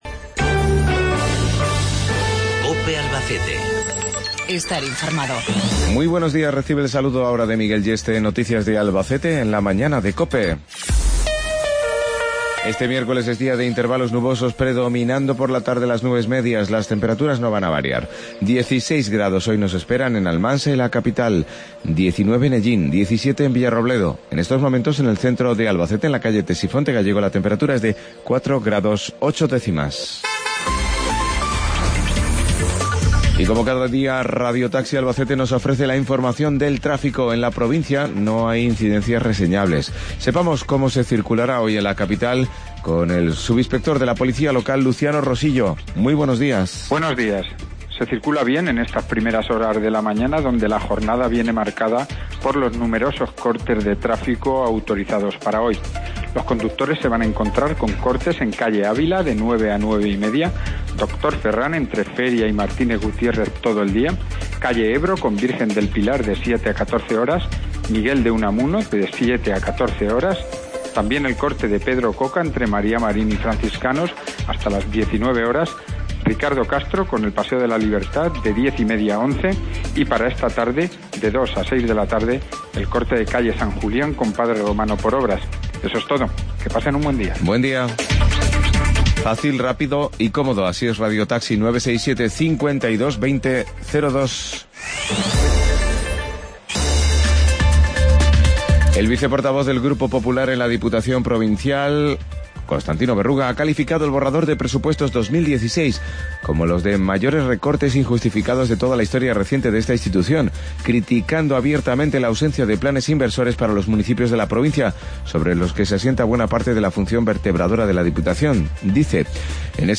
160316 Informativo Local 823